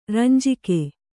♪ ranjike